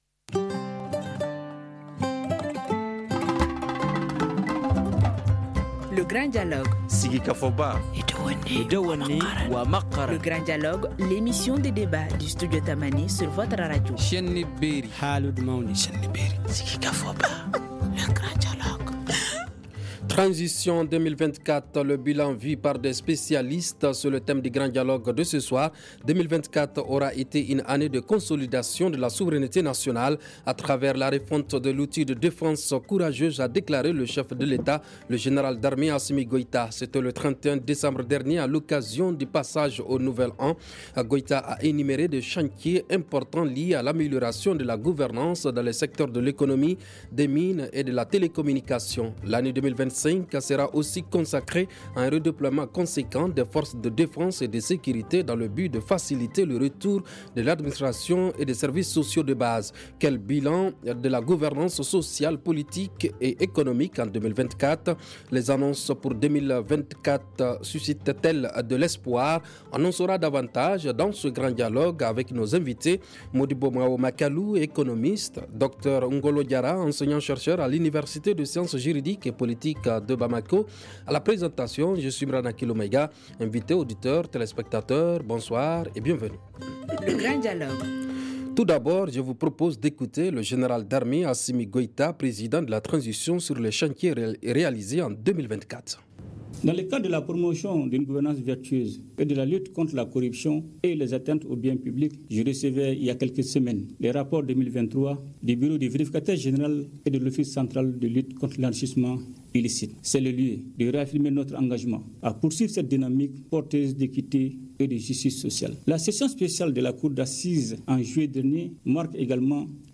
L’année 2025 sera aussi consacrée à un redéploiement conséquent des forces de défense et de sécurité dans le but de faciliter le retour de l’administration et des services sociaux de base Quel bilan de la gouvernance sociale politique et économique en 2024? Les annonces pour 2024 suscitent-elles de l’espoir ? Studio Tamani ouvre le débat :